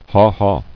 [haw-haw]